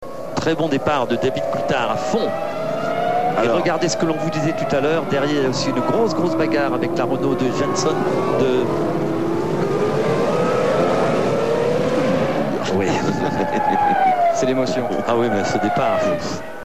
sur TF1
Laffite coupé dans son commentaire !